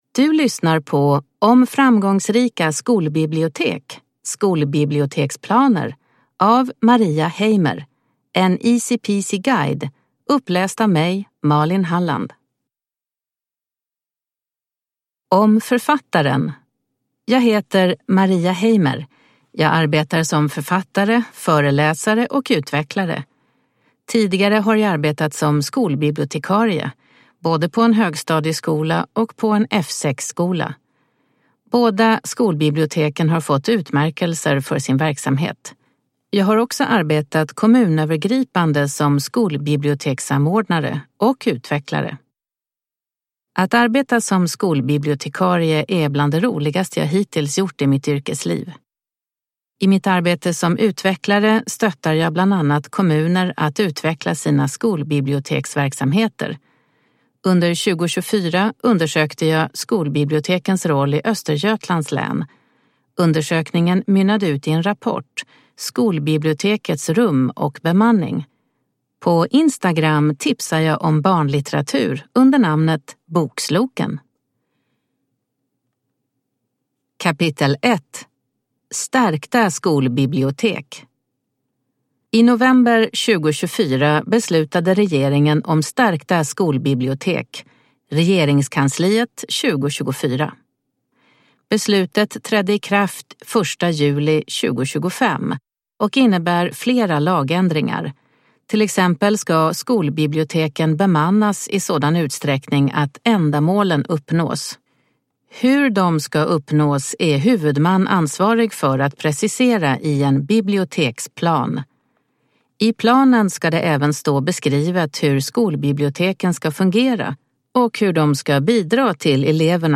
Om framgångsrika skolbibliotek : skolbiblioteksplaner – Ljudbok